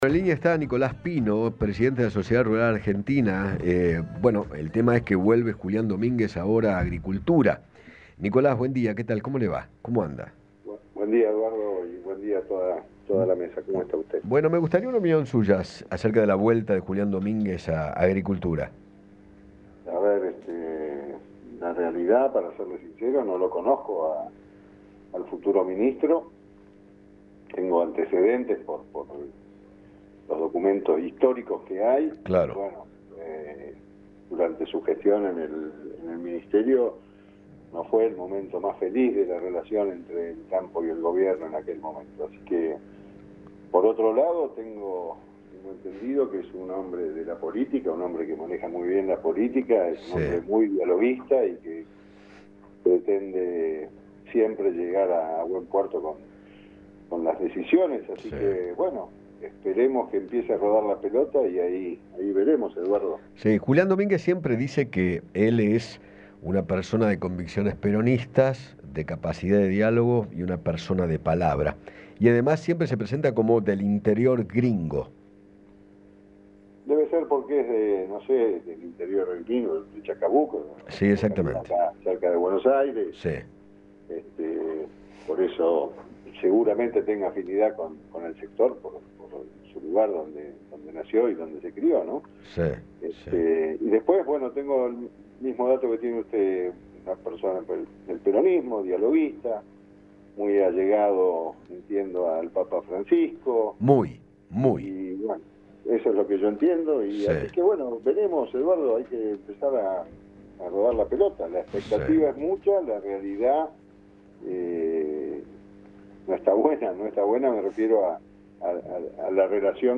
habló con Eduardo Feinmann acerca de la designación de Julián Domínguez como nuevo ministro de Agricultura